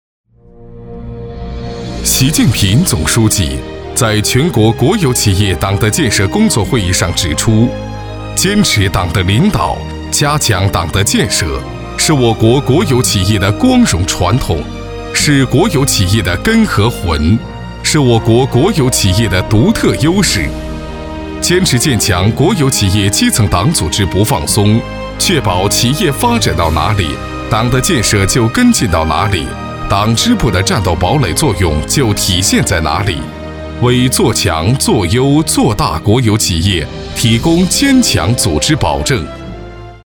男国语217
浑厚大气汇报片